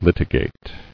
[lit·i·gate]